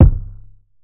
Droop Kick.wav